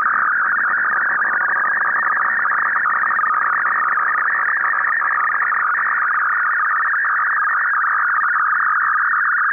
British military 195.3 4-FSK system - audio samples
System sending a test-tape System sending encrypted tfc. back to MFSK-systems page